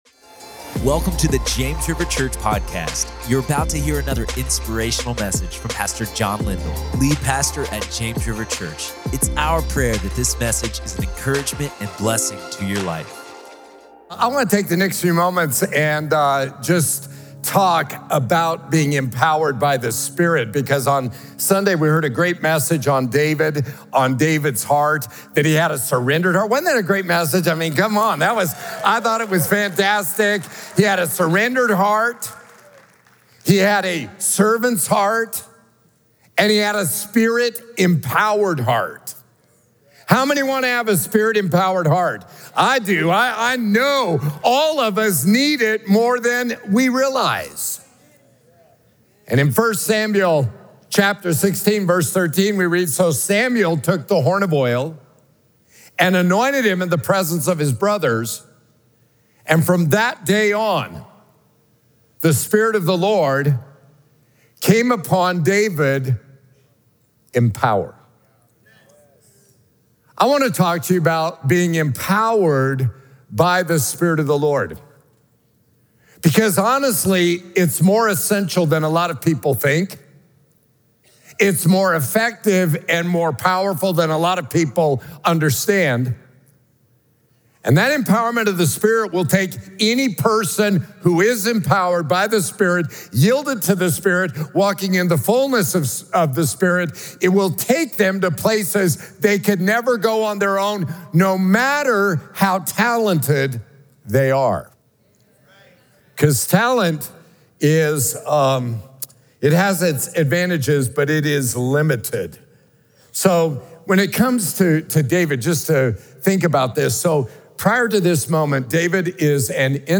Empowered By The Spirit | Prayer Meeting